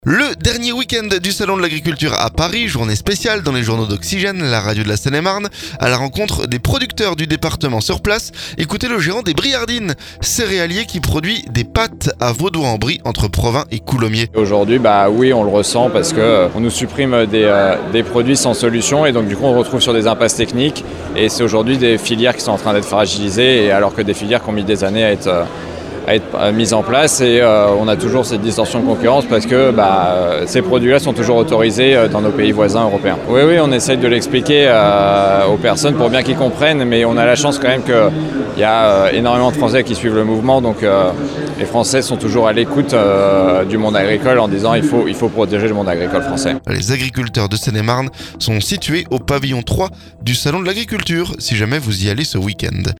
Le dernier week-end du salon de l'agriculture... Journée spéciale dans les journaux d'Oxygène, la radio de la Seine et marne. A la rencontre des producteurs du déepartement sur place.